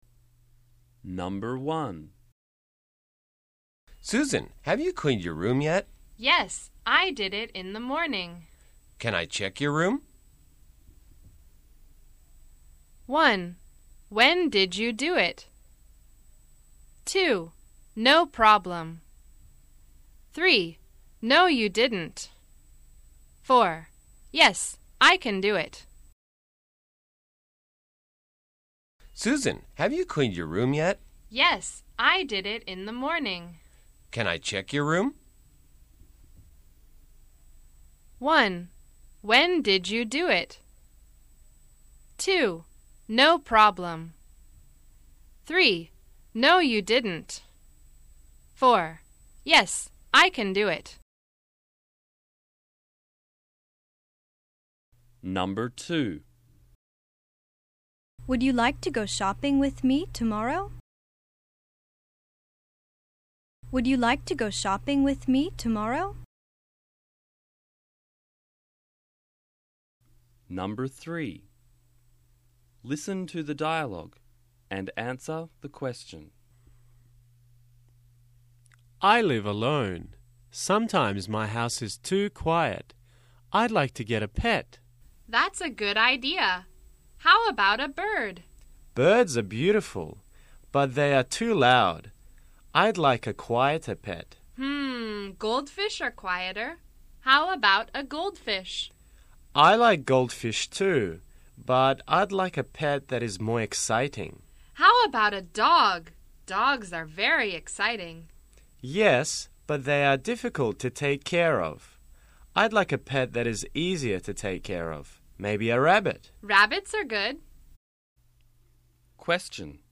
英語リスニング試験 問題例